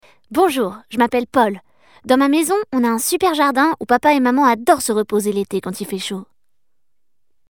Voix Off - Petit garçon